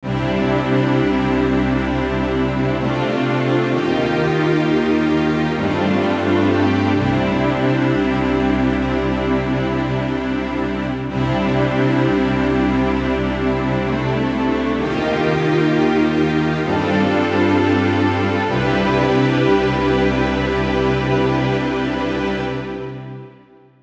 VSTi, какой-то рафинированный.
И влияет на это больше всего нижняя середина, ближе к басам.
Вложения PR-C 035 St.Strings VSTi_eq.wav PR-C 035 St.Strings VSTi_eq.wav 6 MB · Просмотры: 145